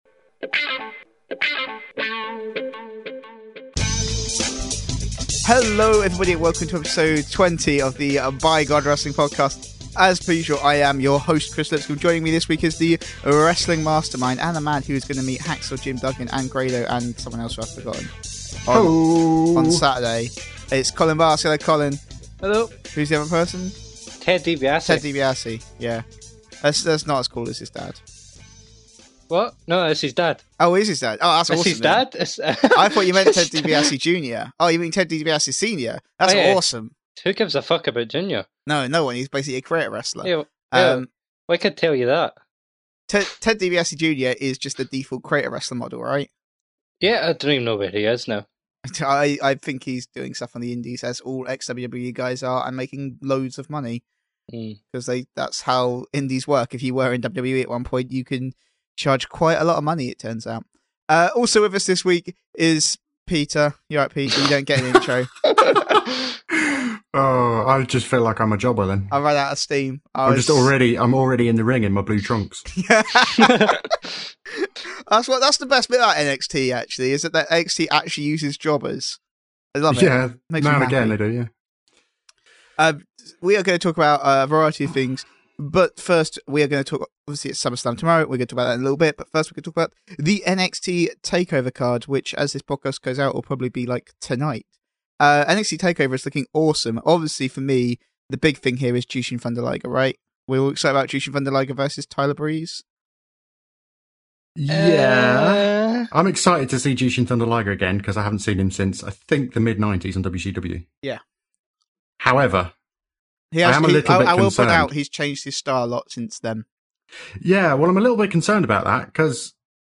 This podcast features strong language, jokes in poor taste and adult themes, if you’re offended by that then we recommend that you don’t listen to it at all.